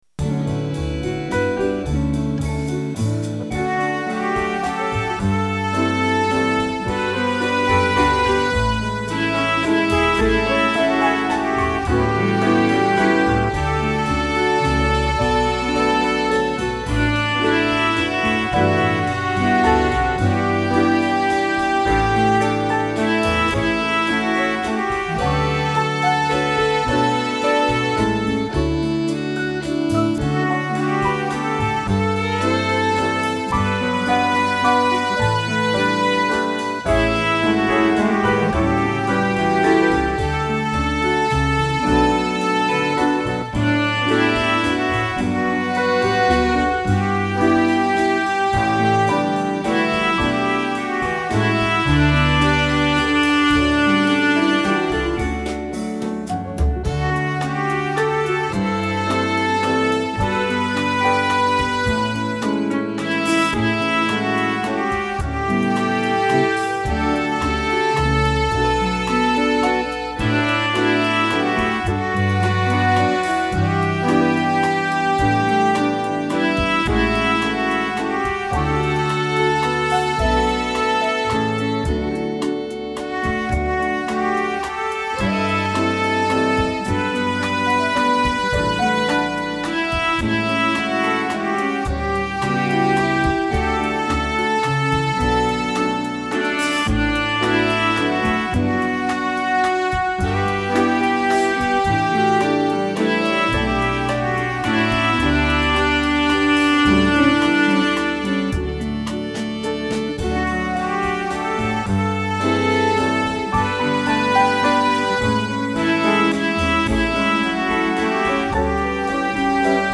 The tempo of my backing is 108.